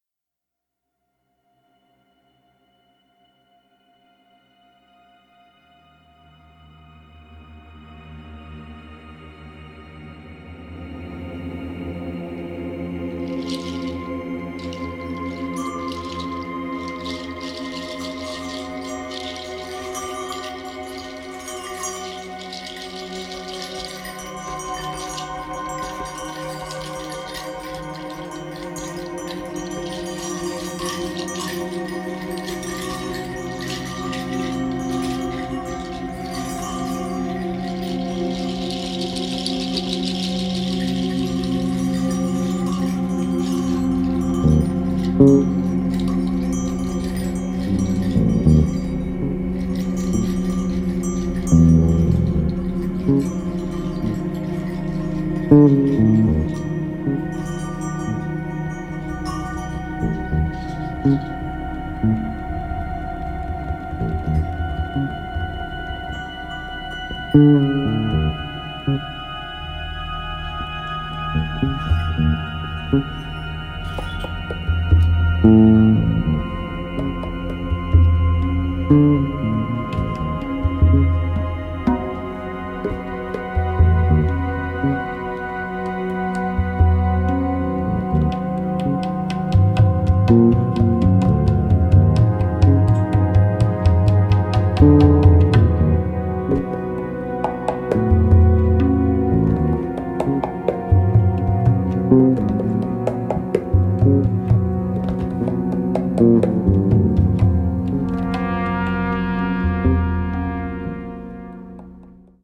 treated C trumpet, percussion, conch shell
electric & acoustic bass
tabla & djembe
moog
harp